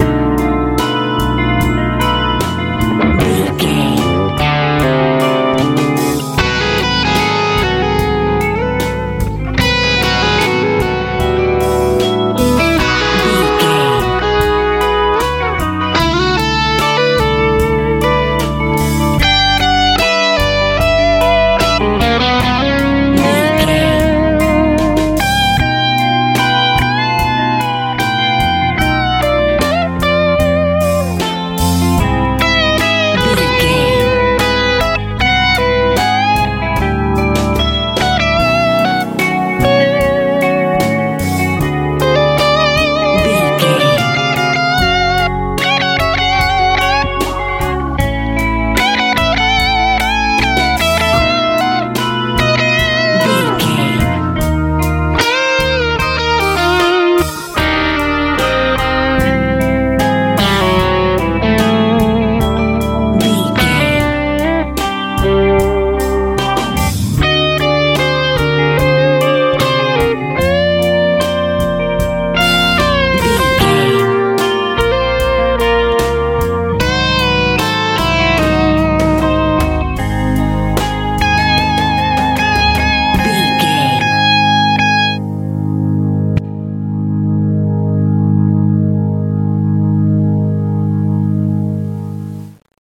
Ionian/Major
intense
organ
electric guitar
bass guitar
drums
suspense